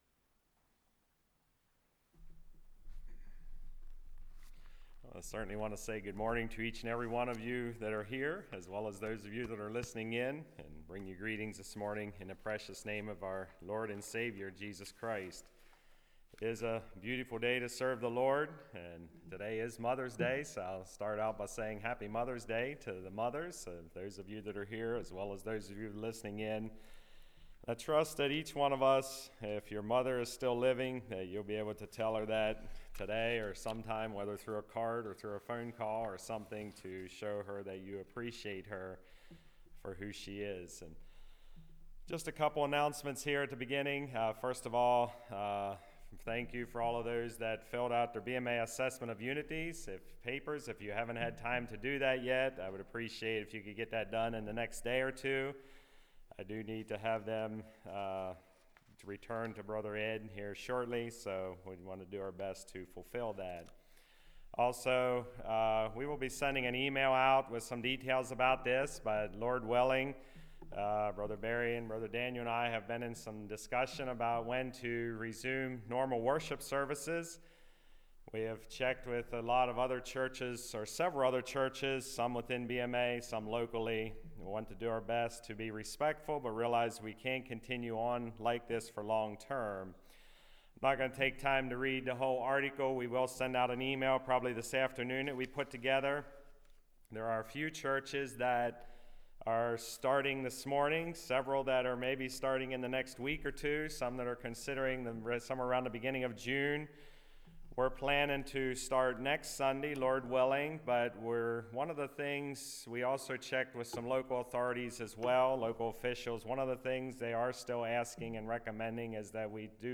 Passage: Proverbs 31 Service Type: Message Bible Text